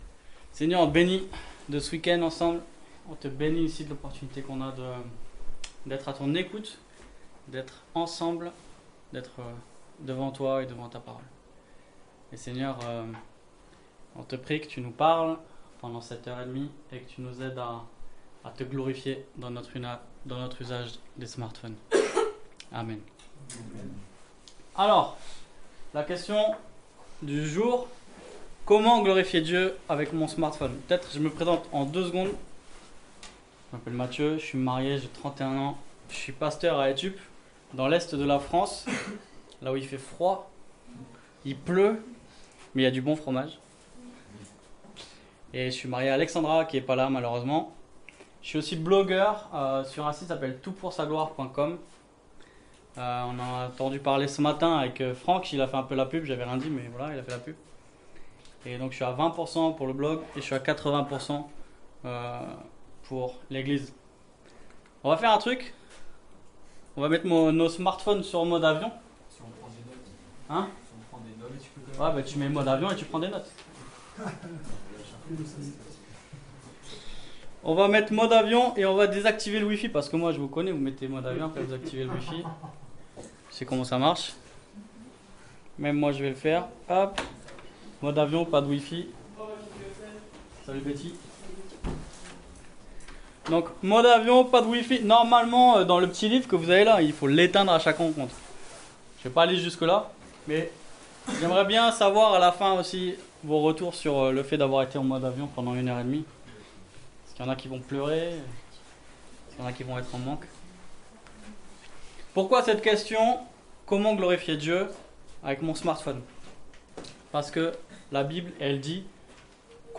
Pâques 2019 - Ateliers